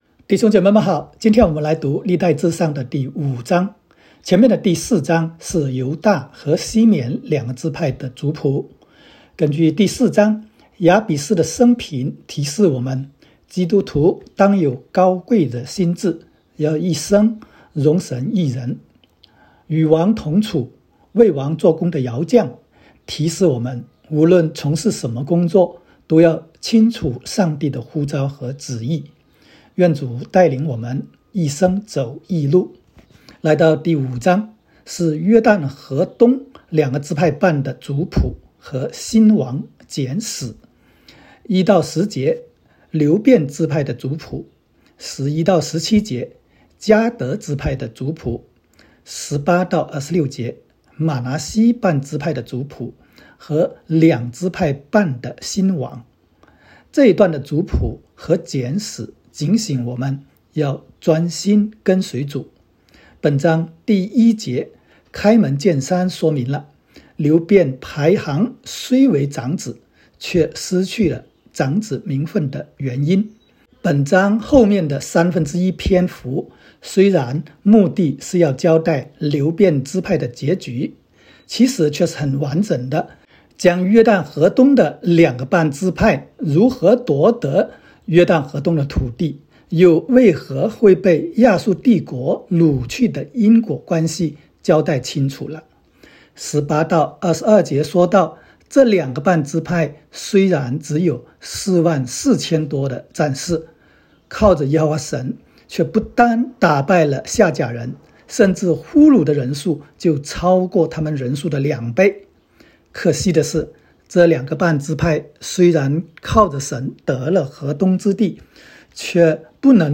代上05（讲解-国）.m4a